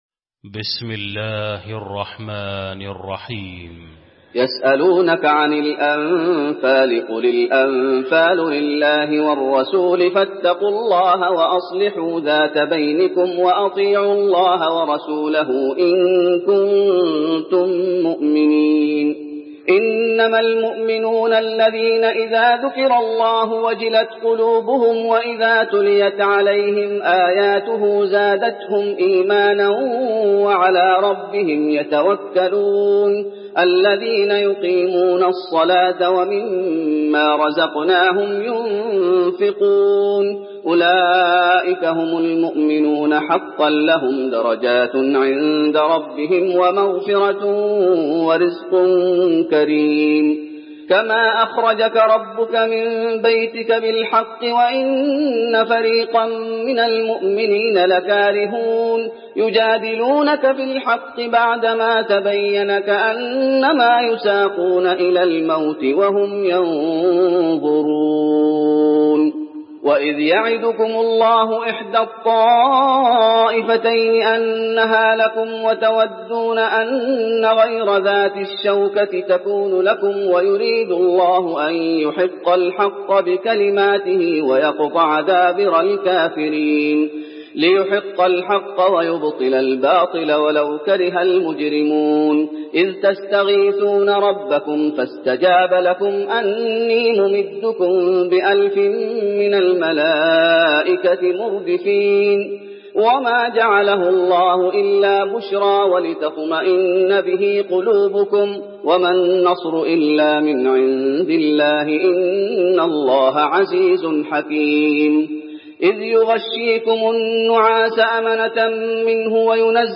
المكان: المسجد النبوي الأنفال